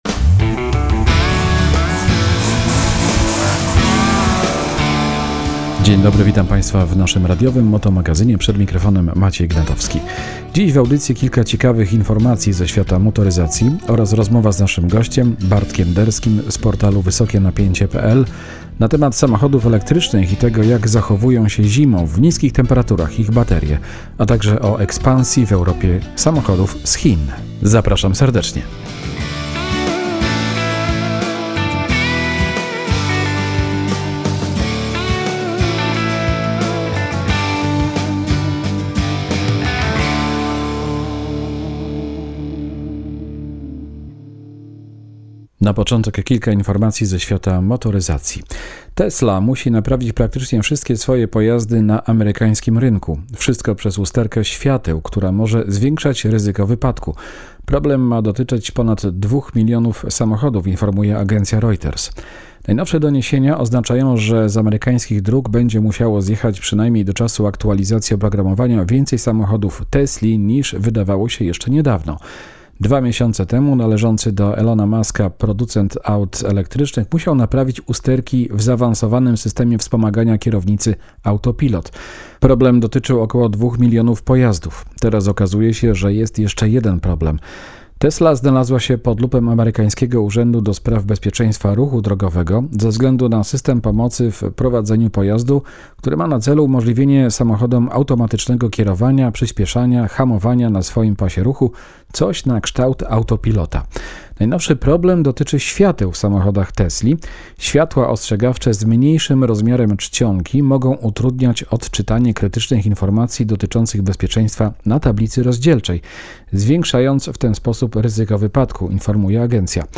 rozmowa z naszym gościem